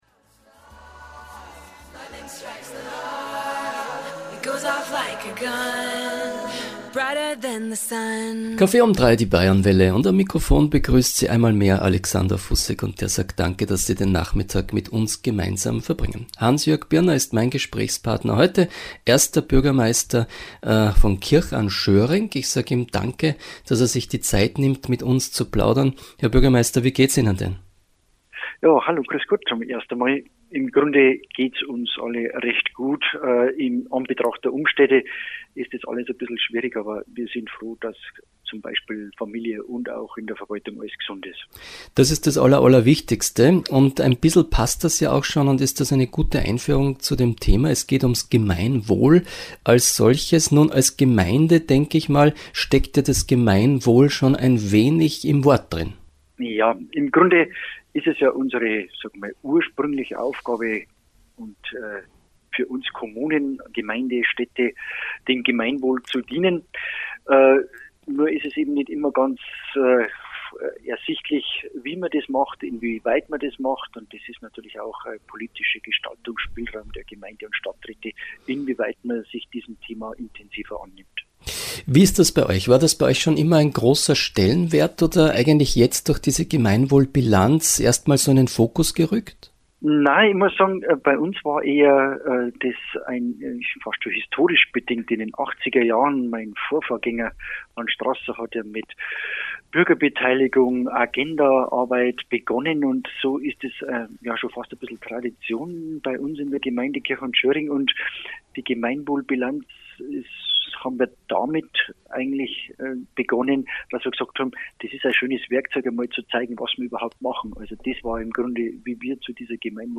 Verantwortung zeigen – Herr Birner, 1. Bürgermeister von Kirchanschöring, berichtet
Hans-Jörg Birner berichtete vergangenen Freitag in der Bayernwelle über die Gemeinwohl Bilanz, den Mehrwert des Prozesses der Bilanzierung und die Energie, die entsteht, wenn Bürger, egal ob jung oder alt, in Entwicklungsprozesse mit eingebunden sind.